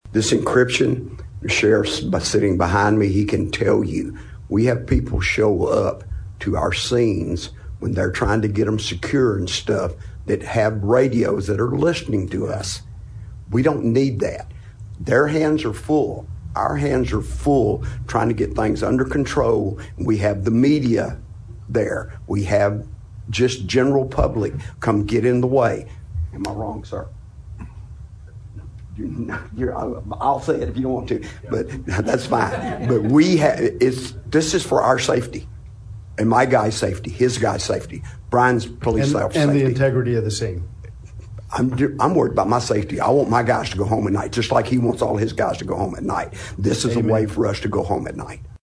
Brazos County precinct two constable Donald Lampo expressed his support for blocking public access during Tuesday’s (December 9) county commission meeting.
Click below to hear Donald Lampo’s comments: